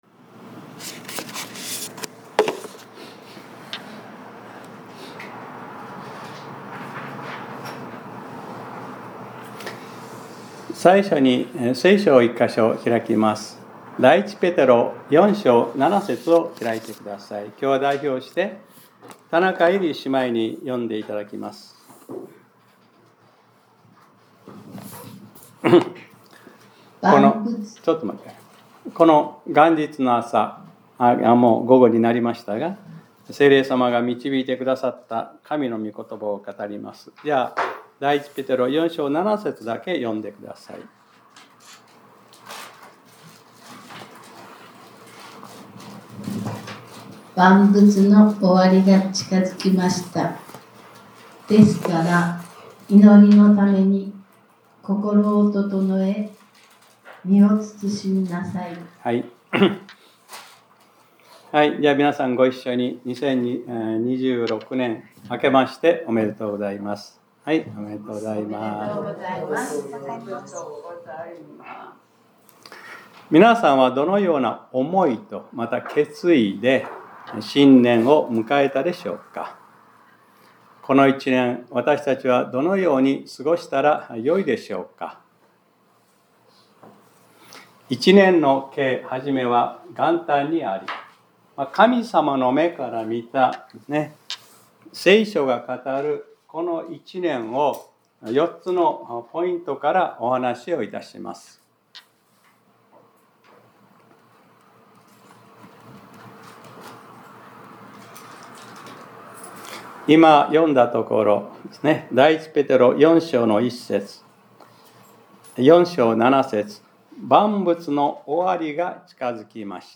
2026年01月01日（日）礼拝説教『 万物の終わりが近づきました 』 | クライストチャーチ久留米教会